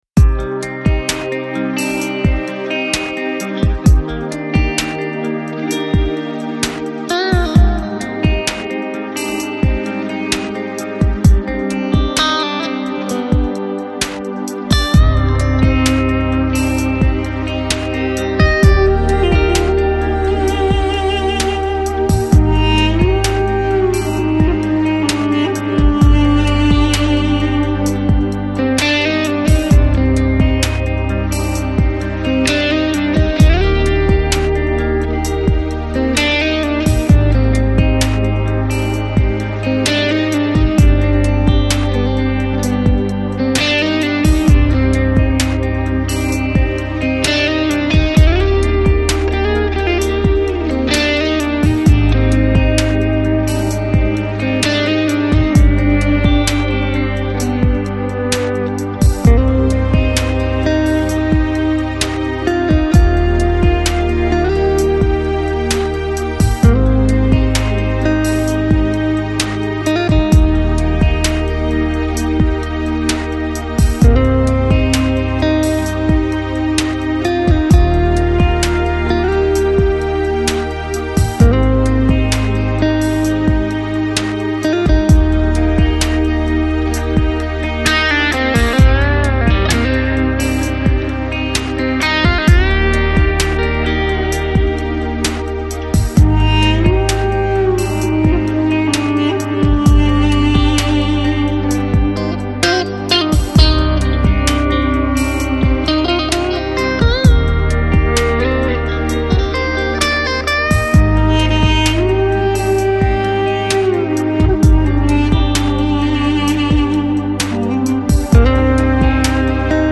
Жанр: Chill Out, Instrumental Guitar Rock